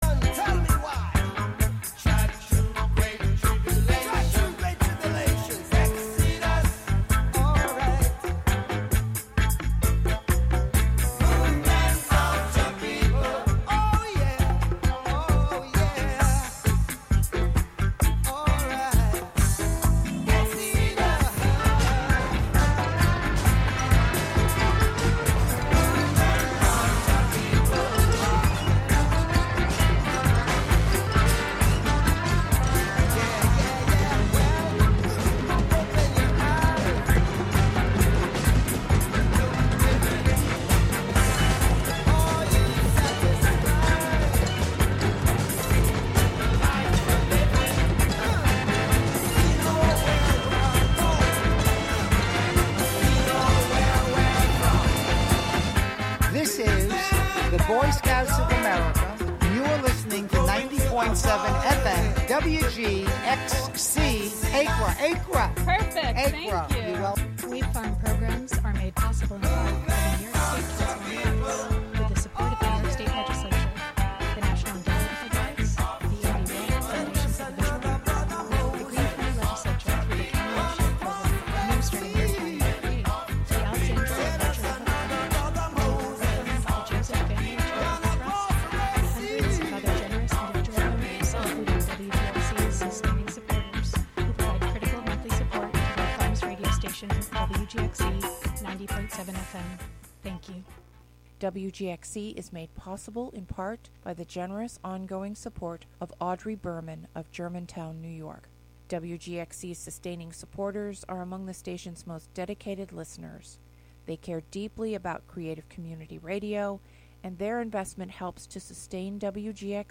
Monthly excursions into music, soundscape, audio document and spoken word, inspired by the wide world of performance. Live from Ulster County.